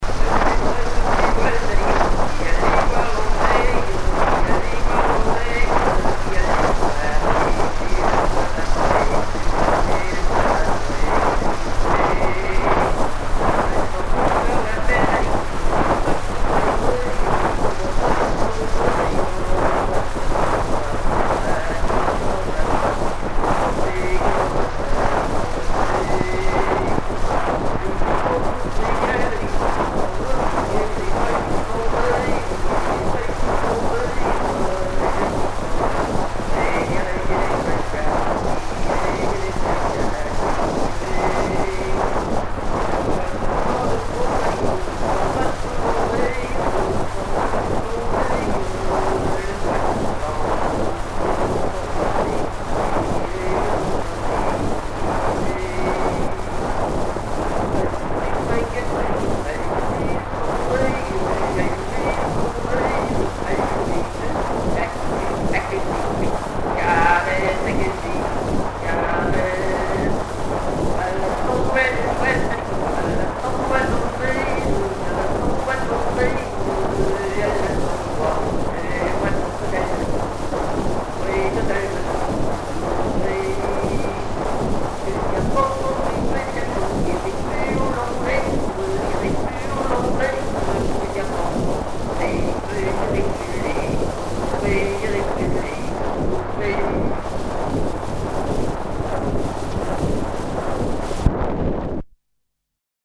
Note 78 t. pyral
Genre laisse
Prises de sons diverses
Pièce musicale inédite